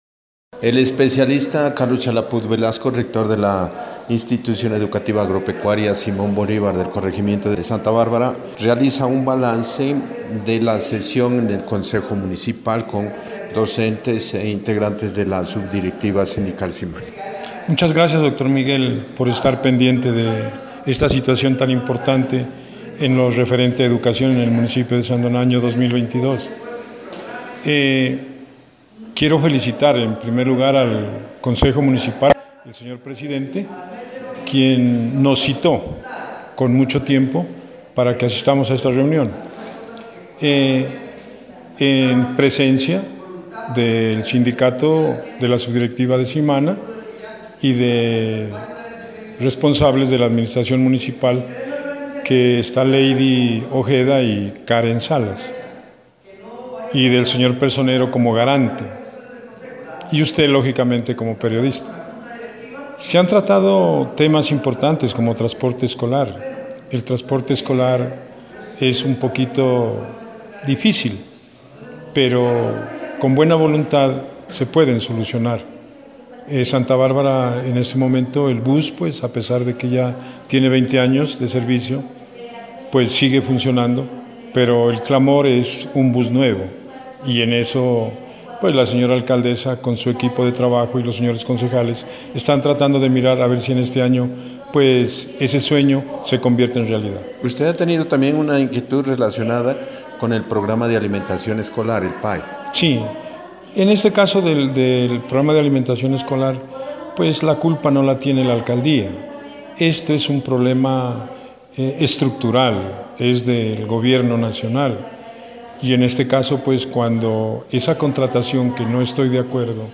Entrevistas: